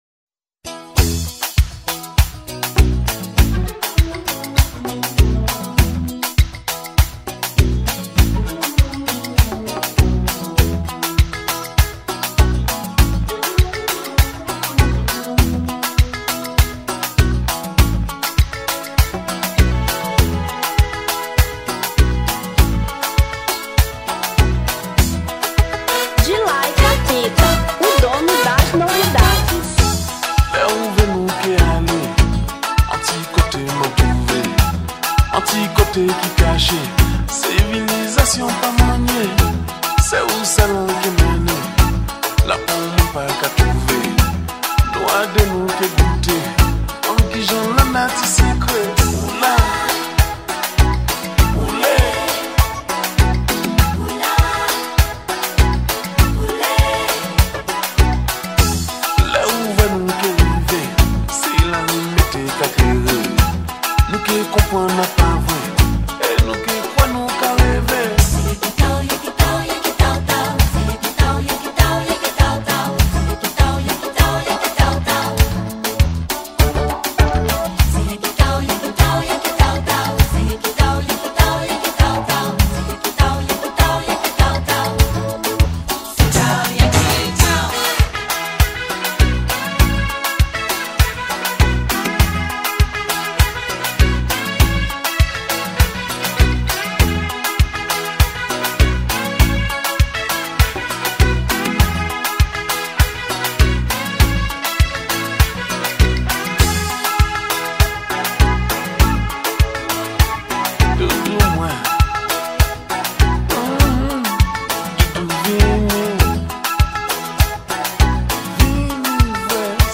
Zouk 1989